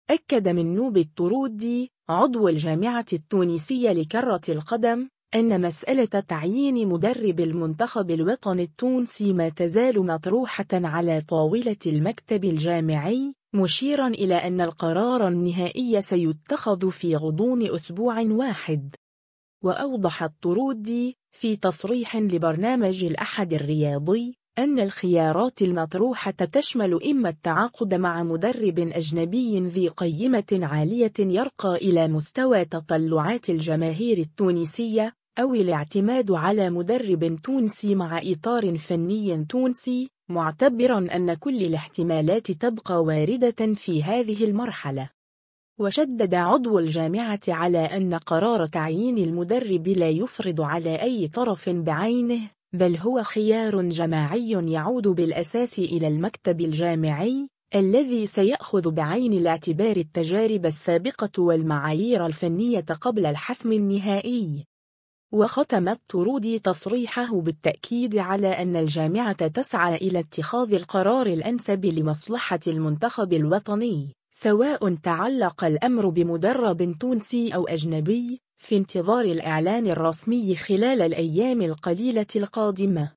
في تصريح لبرنامج الأحد الرياضي